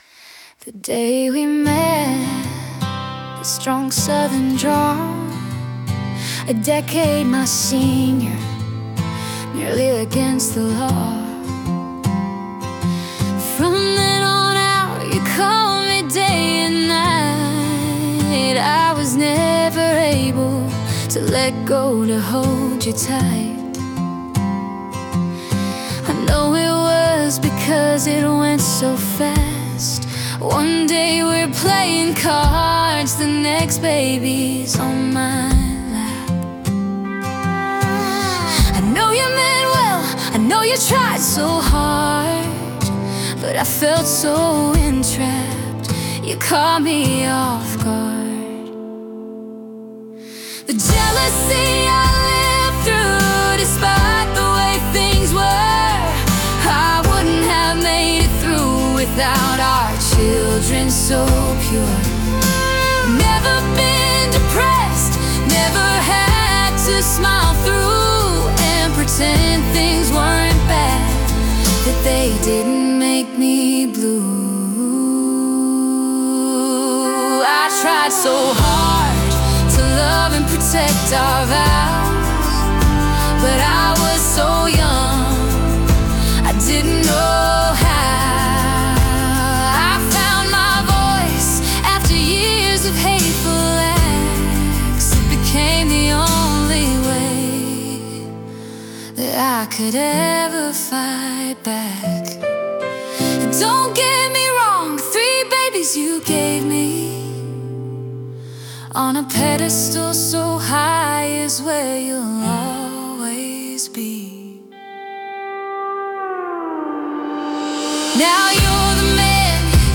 country song
Category: Country